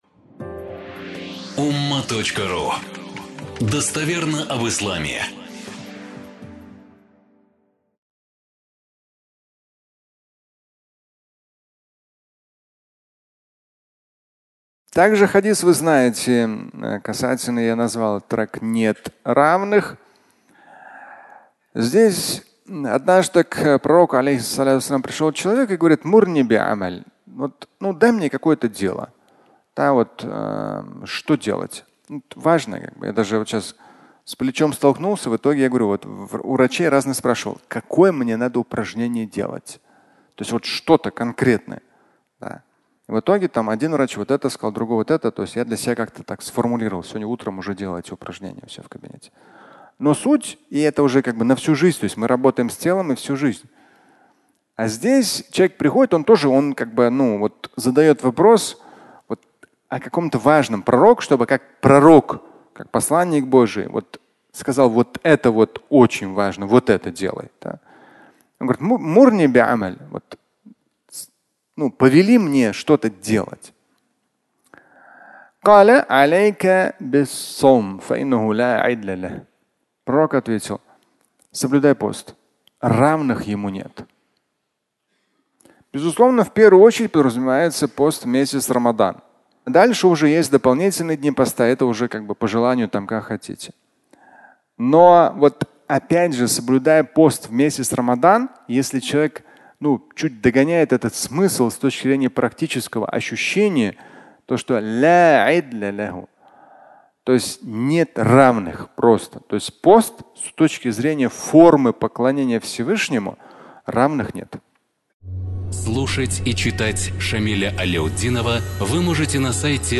Нет равных (аудиолекция)